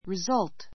rizʌ́lt